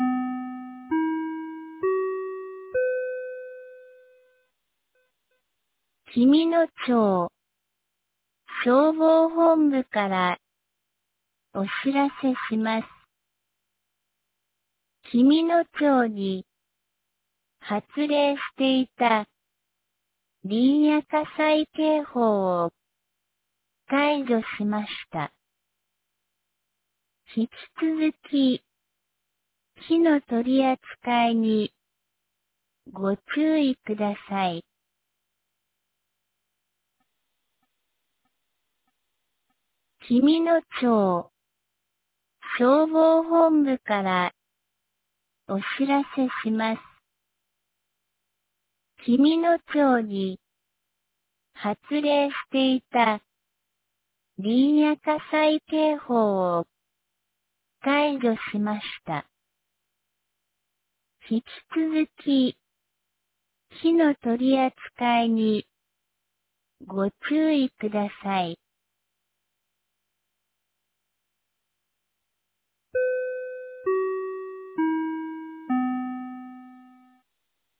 2026年02月23日 10時04分に、紀美野町より全地区へ放送がありました。